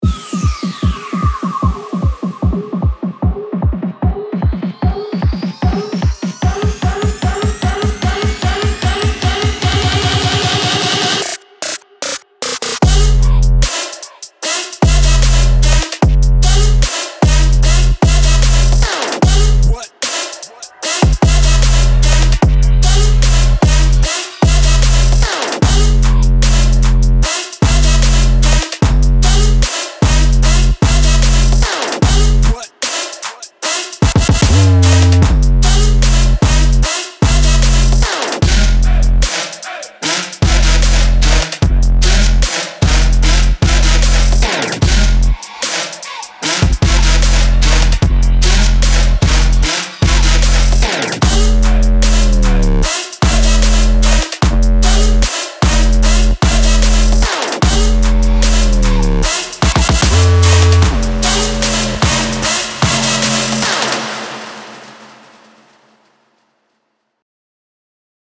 【Dubstep/Chillstep风格Kick 2预置包】Sonic Academy – Kick 2 Vol 8 Trap and Dubstep
每次都有50个令人难以置信的黑暗和粗糙的鼓点预设和94个精心制作的自定义喀哒声，可为您提供充满舞台的潜艇。
在这里，您会发现震撼的踢腿，扭曲的低音，令人赞叹的低音线，所有这些设计都可以创造出最重的节奏。